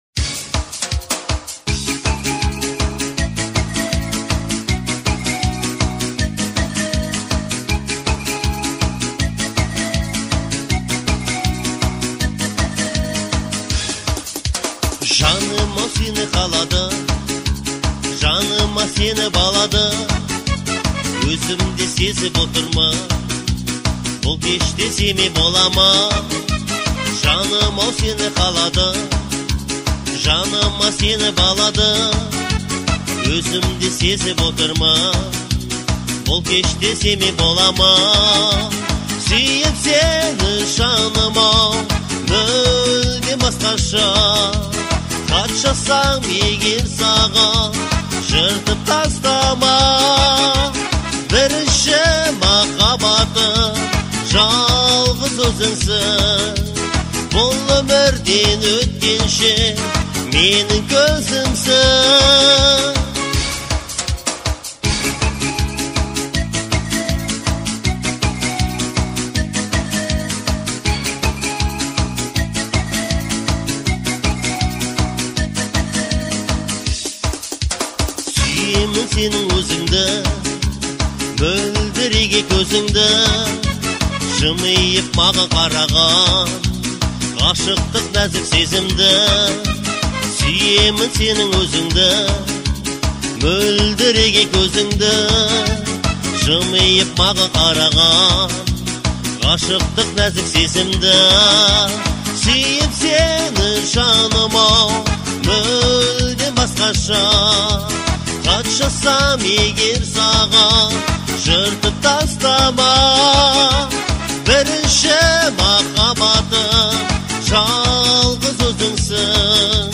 это трогательный трек в жанре поп с элементами фолка